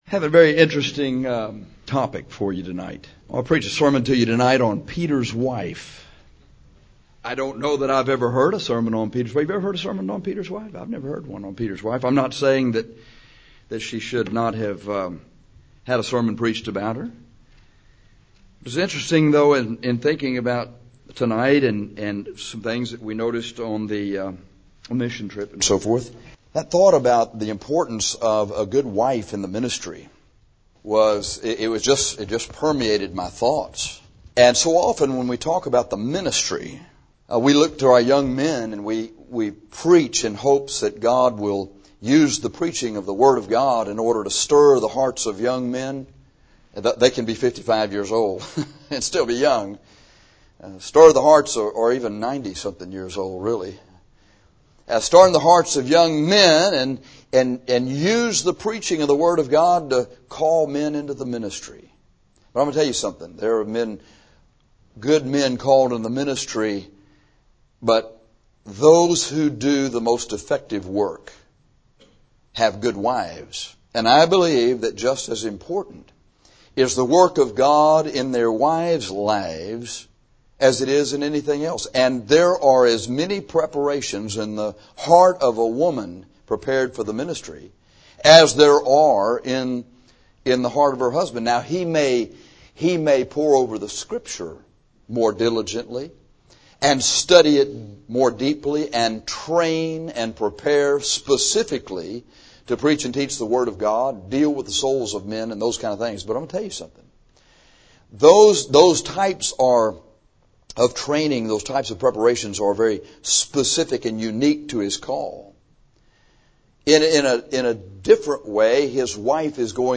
We often speak to men when preaching about the ministry.